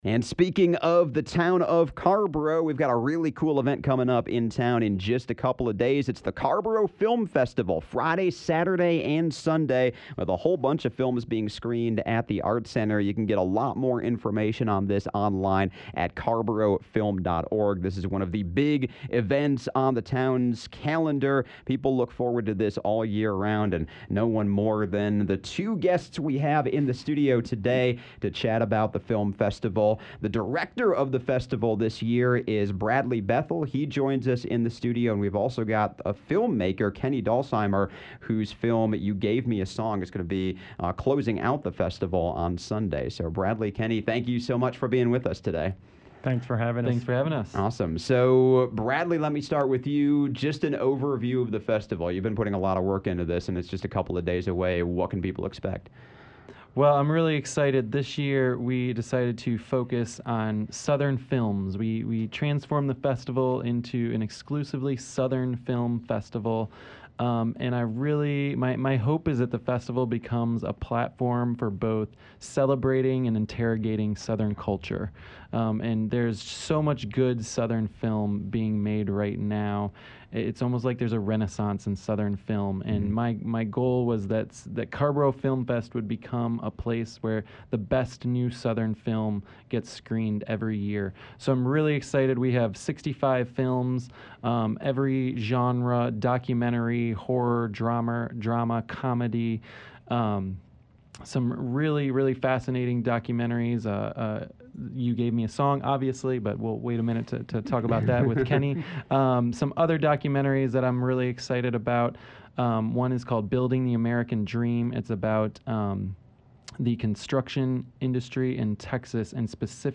Conversation sponsored by the Carrboro Film Festival.